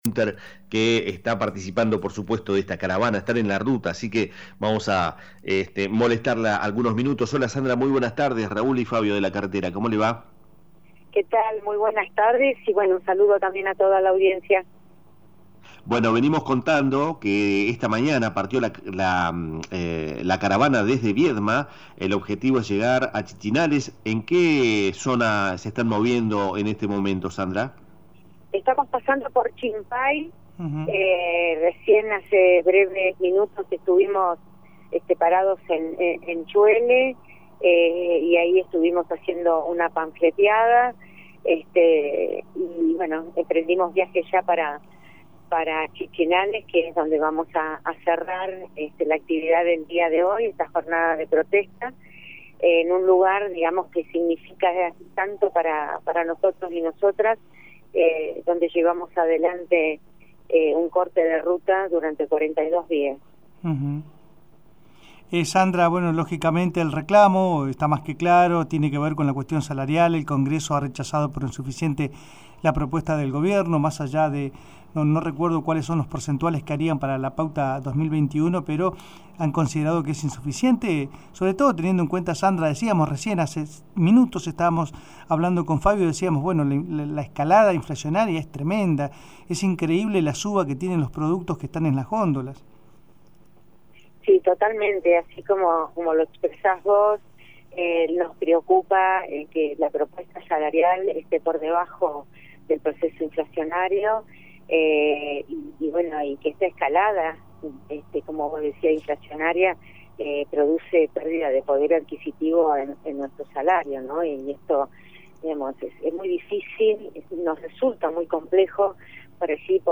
Entrevistas radiofónica
Entrevista